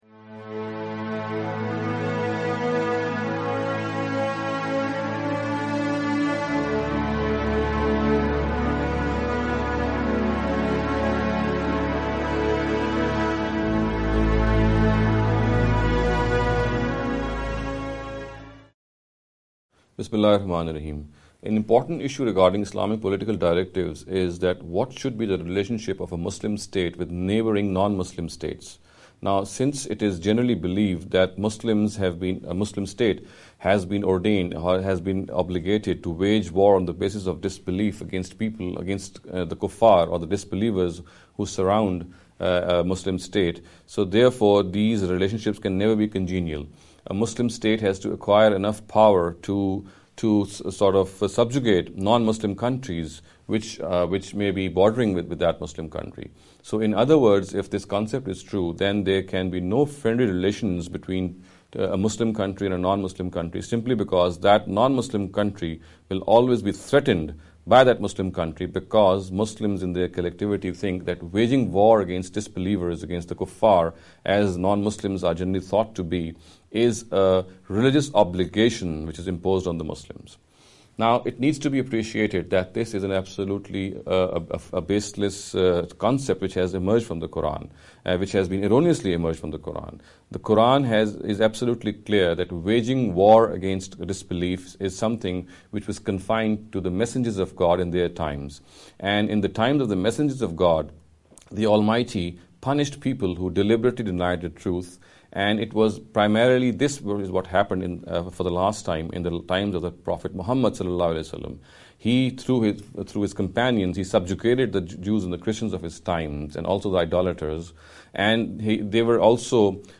This lecture series will deal with some misconception regarding the Concept of the Hereafter.